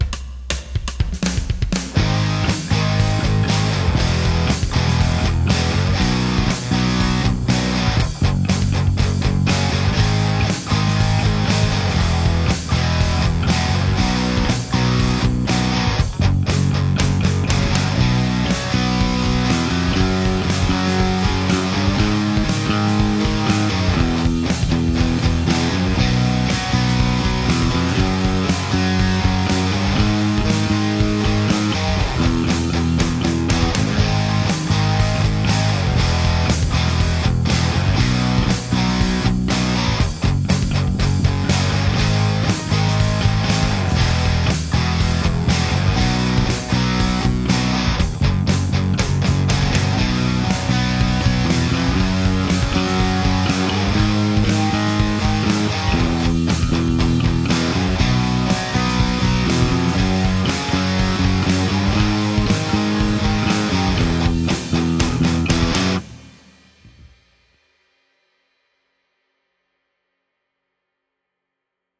react_rock_backing.m4a